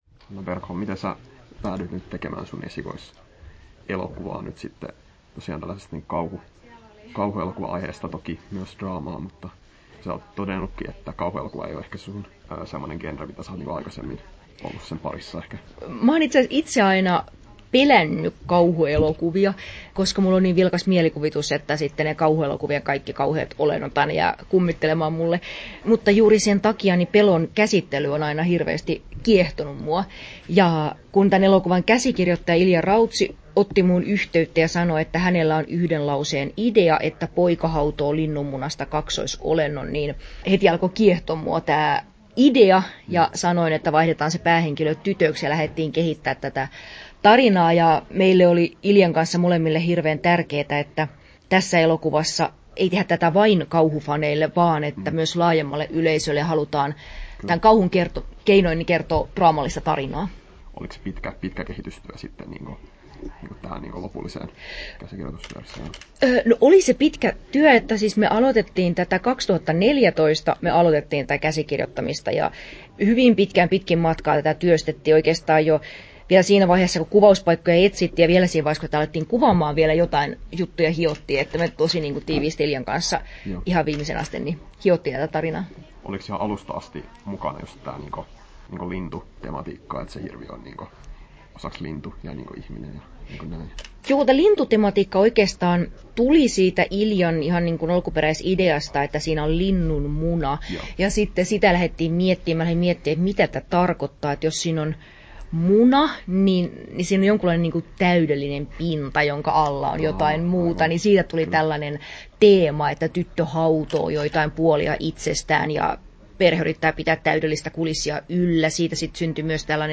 Haastattelussa
8'32" Tallennettu: 16.2.2022, Turku Toimittaja